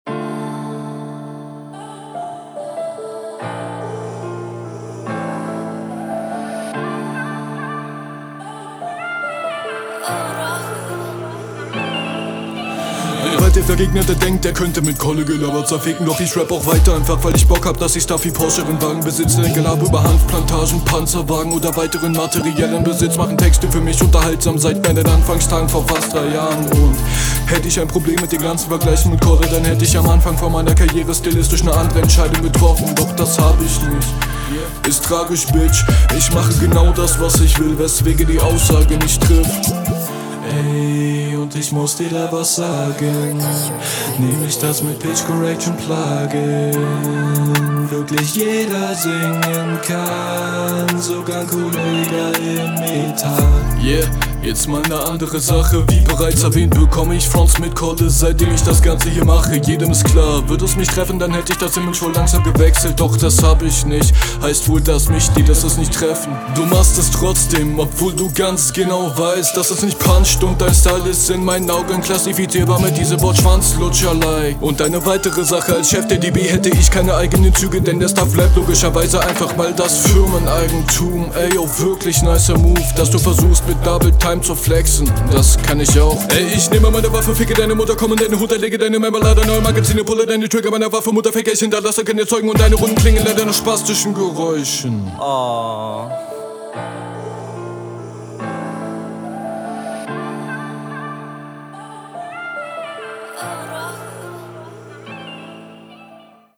uuuuh, da kommt der sound den ich von dir kenne. die eine sehr schnell gerappte …